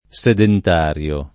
sedentario
vai all'elenco alfabetico delle voci ingrandisci il carattere 100% rimpicciolisci il carattere stampa invia tramite posta elettronica codividi su Facebook sedentario [ S edent # r L o ] agg.; pl. m. -ri (raro, alla lat., -rii )